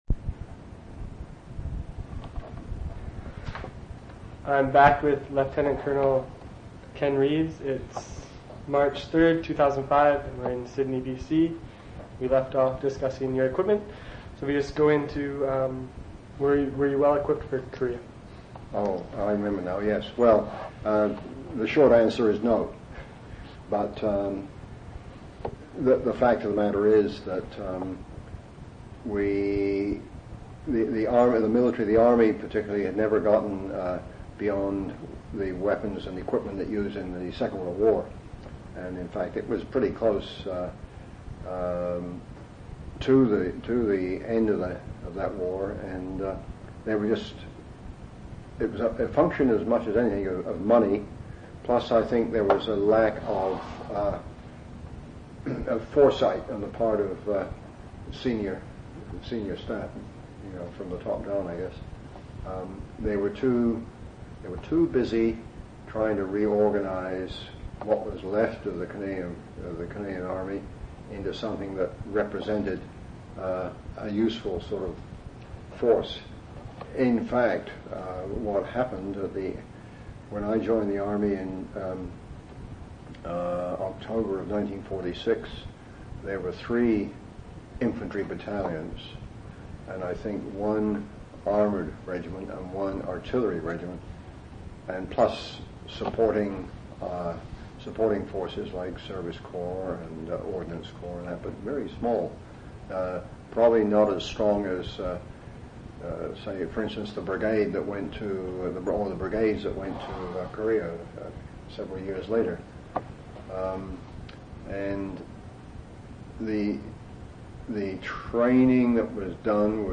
In Collection: Canadian Military Oral Histories